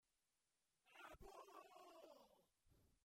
Tag: 112 bpm House Loops Vocal Loops 526.54 KB wav Key : Unknown